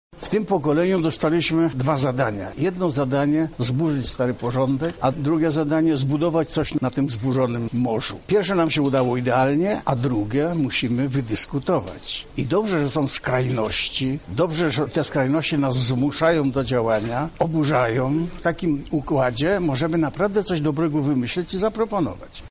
Lech Wałęsa podczas wizyty w Lublinie wzywał do zmian w kraju
Niech to młode pokolenie przebuduje Polskę, Europę i świat – mówił Lech Wałęsa, były prezydent RP: